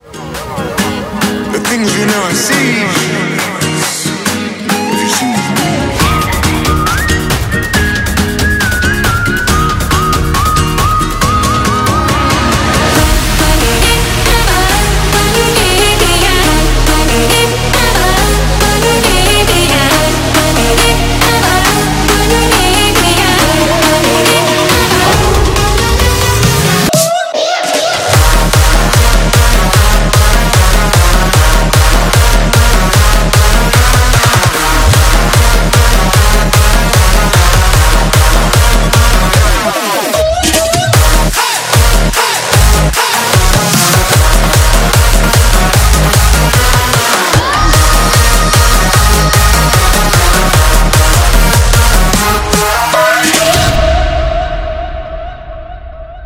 • Качество: 320, Stereo
свист
жесткие
EDM
electro house